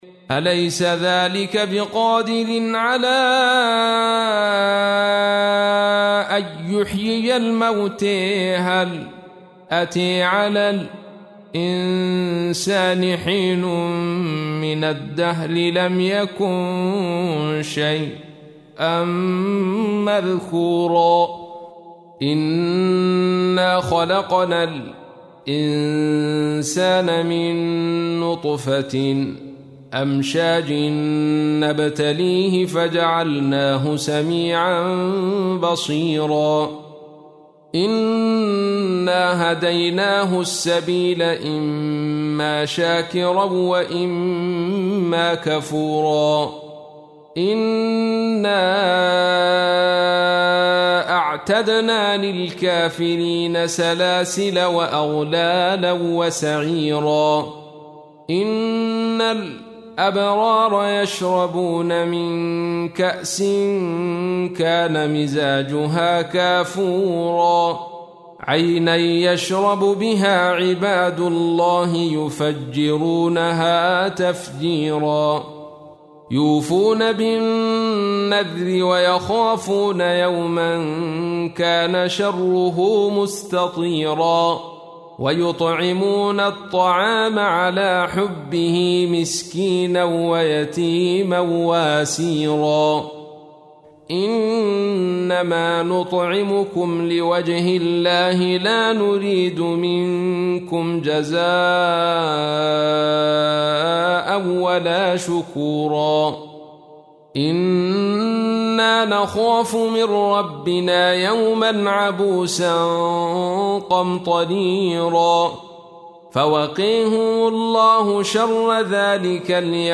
تحميل : 76. سورة الإنسان / القارئ عبد الرشيد صوفي / القرآن الكريم / موقع يا حسين